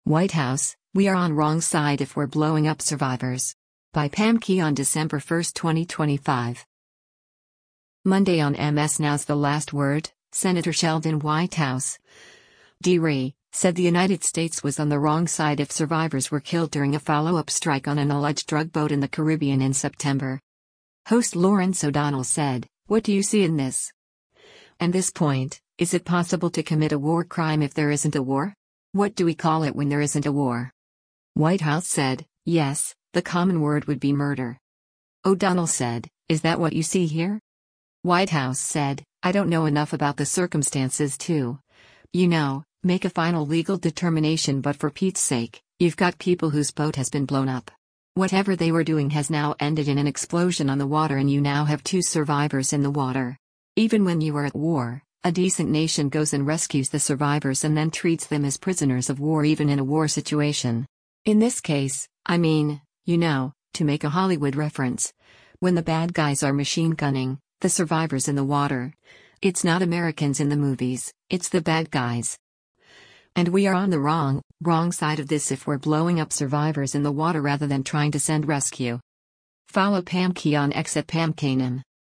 Monday on MS NOW’s “The Last Word,” Sen. Sheldon Whitehouse (D-RI) said the United States was on the “wrong side” if survivors were killed during a follow-up strike on an alleged drug boat in the Caribbean in September.